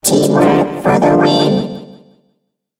evil_rick_start_vo_06.ogg